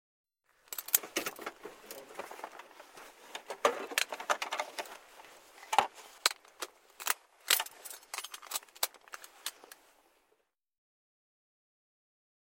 Звук застегивающегося ремня безопасности в вертолете